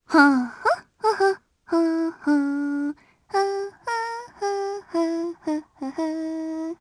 Viska-Vox_Hum_jp.wav